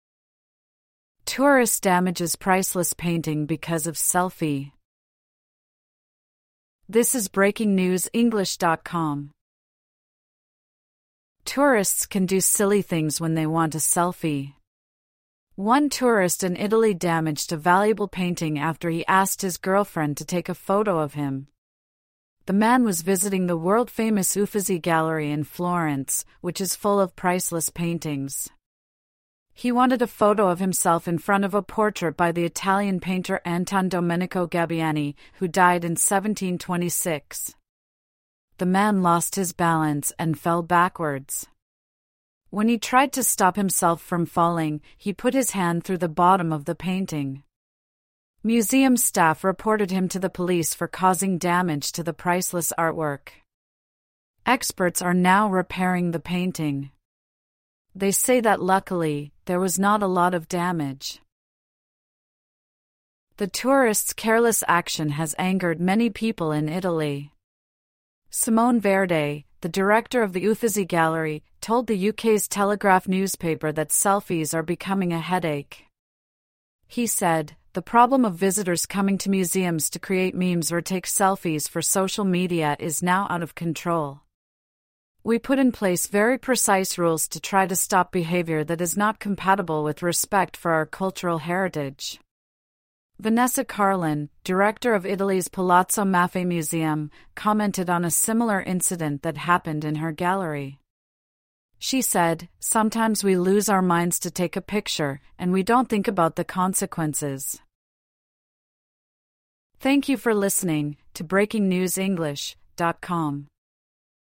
AUDIO (Normal)